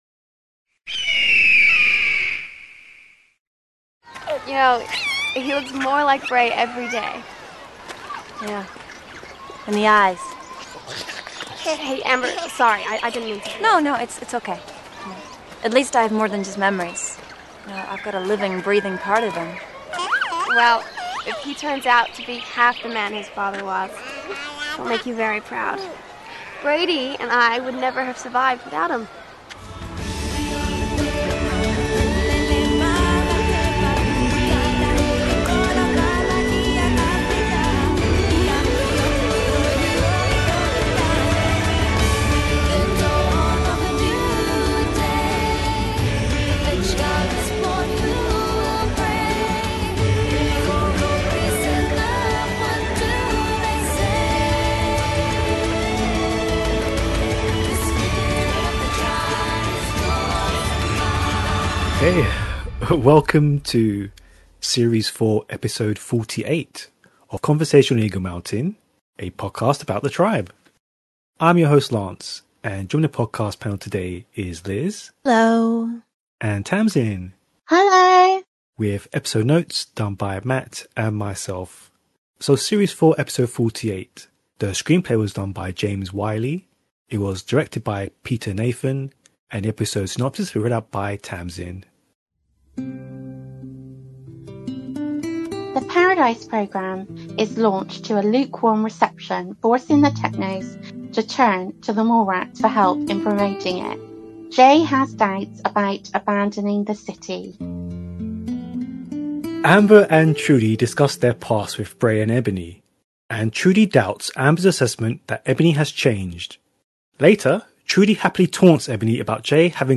Several fans of The Tribe come together to discuss the episode-to-episode details of the show.